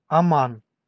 Ääntäminen
Ääntäminen US Haettu sana löytyi näillä lähdekielillä: englanti Käännös Ääninäyte Erisnimet 1.